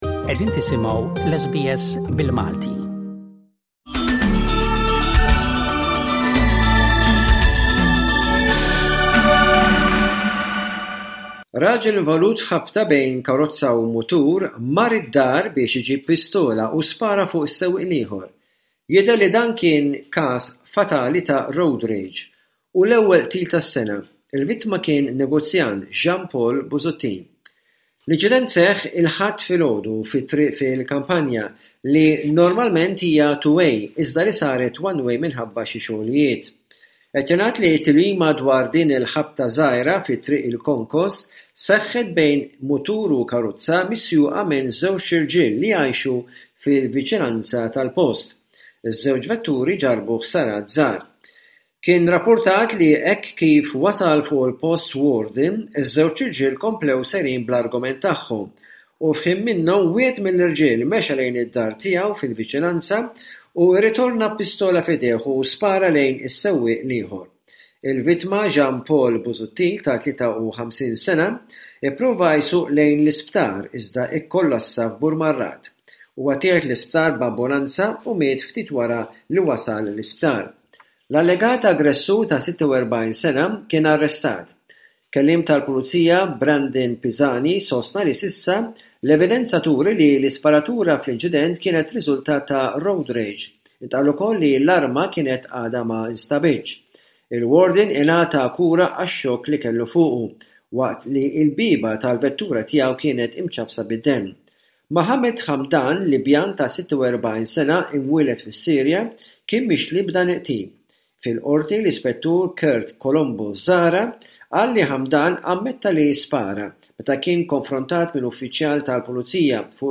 Aħbarijiet minn Malta: 04.07.25